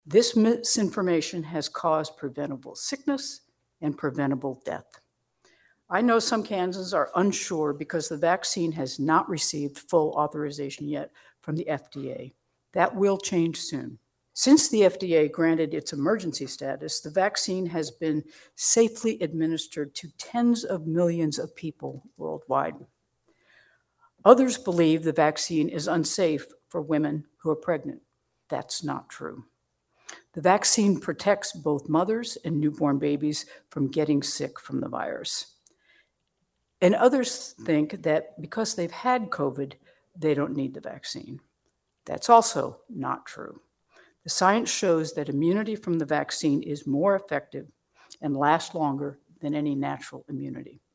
During a special news conference, Friday morning from Saint Lukes Hospital in Kansas City the governor addressed a number of topics from hospital and ICU capacity in the state, to misinformation on the pandemic and the potential for spread of the virus in Kansas schools.